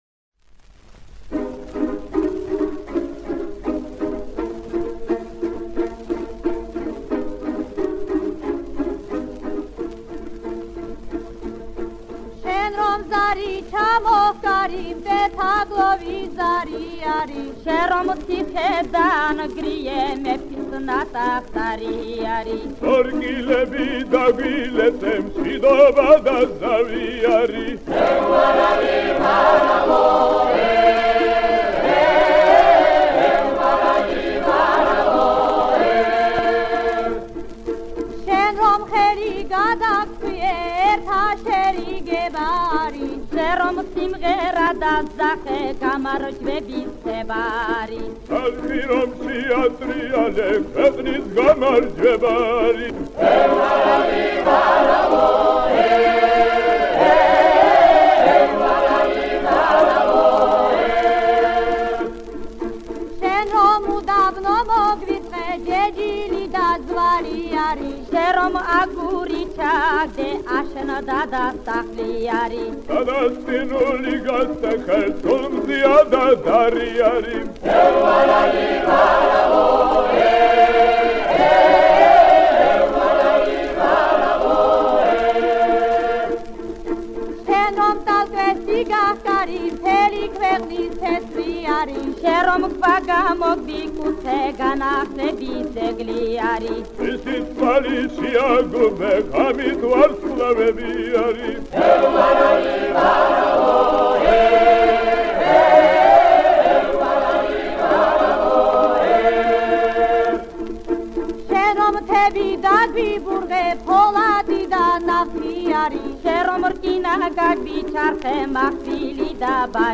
Описание: Грузинская песня